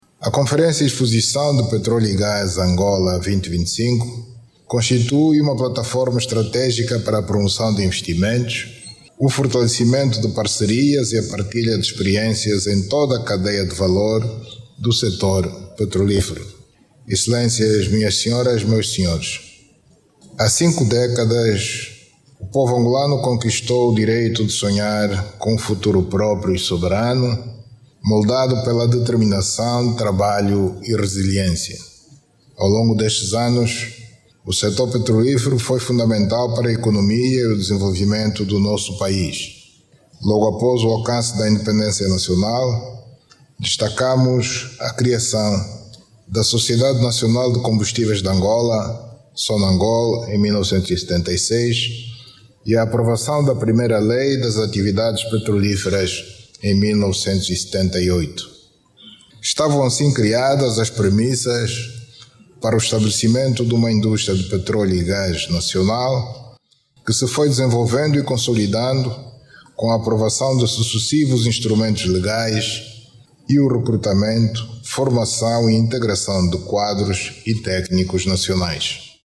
João Lourenço que falava esta amanhã na abertura da conferência, sublimou que ao longo destes anos o sector dos petróleos foi determinante para a economia e desenvolvimento do país.